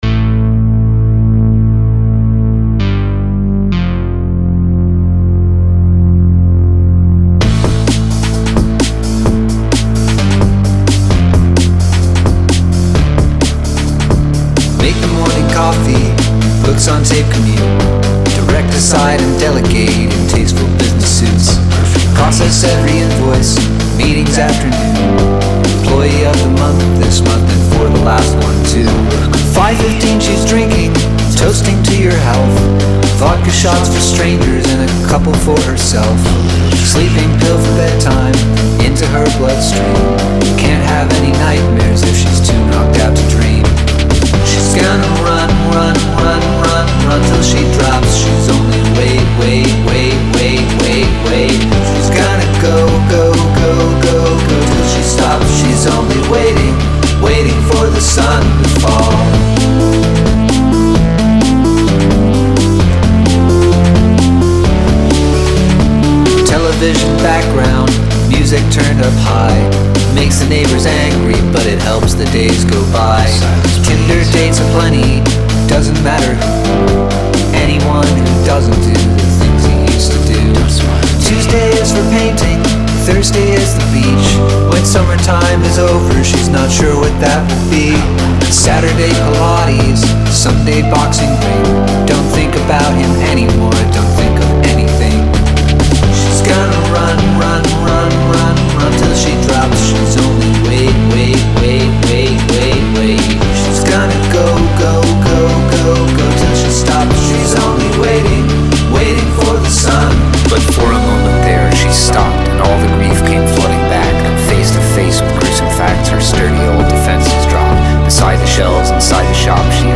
Props on making the spoken word bridge sound great.